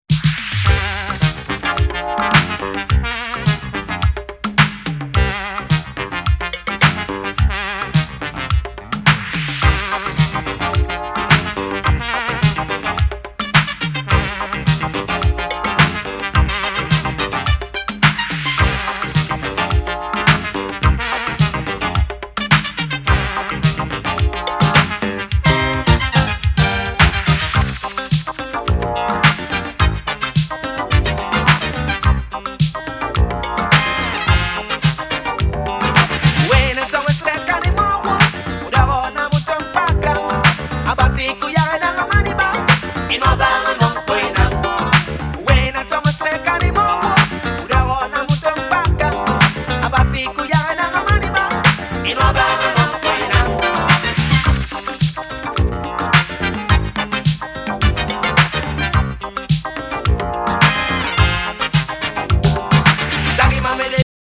Nigerian music